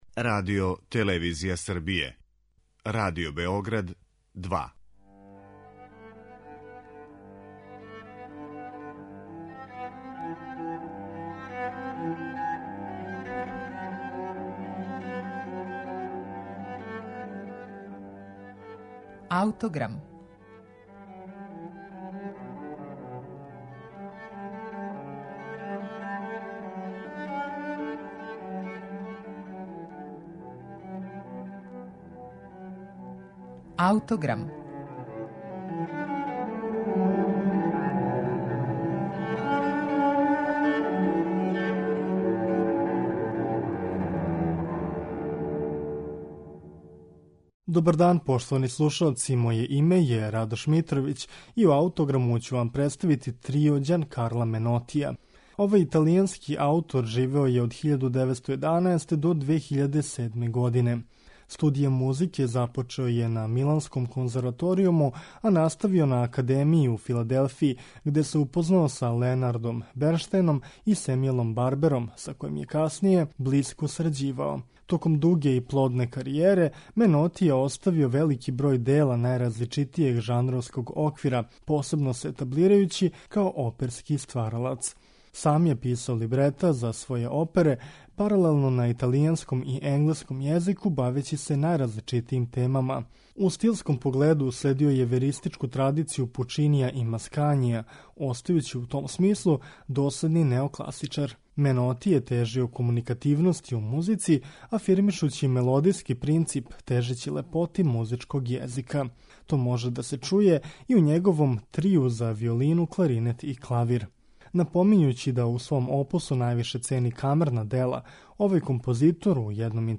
виолина, кларинет, клавир